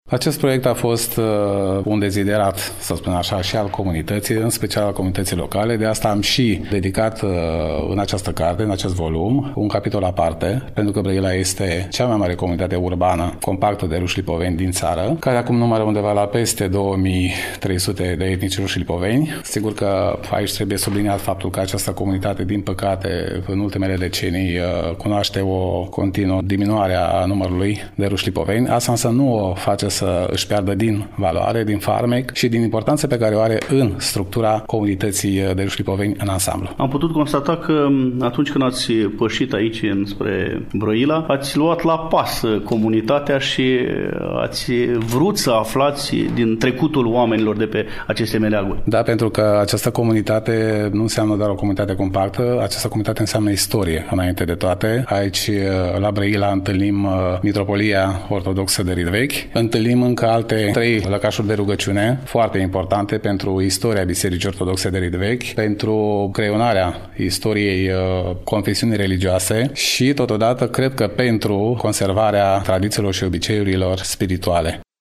În emisiunea de astăzi poposim acasă la rușii lipoveni din Brăila, mai exact în incinta sediului cultural al Comunității din cartierul Pisc, de pe strada Alexandru Davila, Numărul 13.